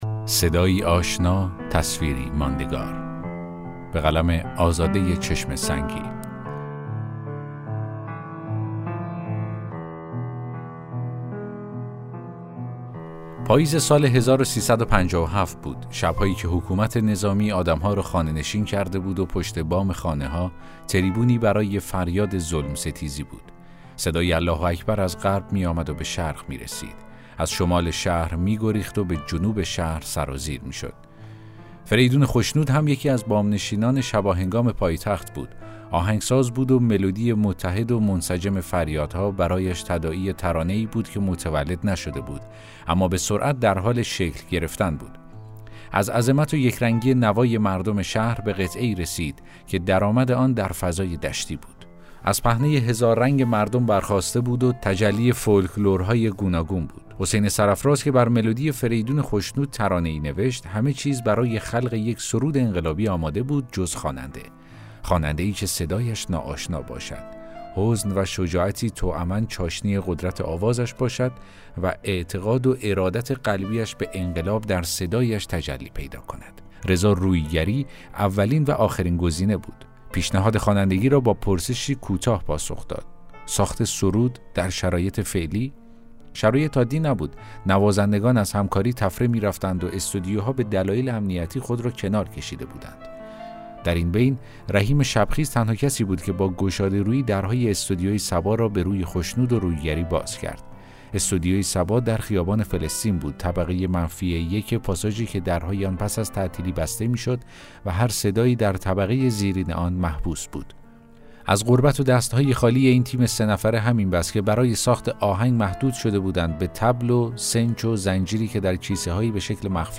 داستان صوتی: صدایی آشنا، تصویری ماندگار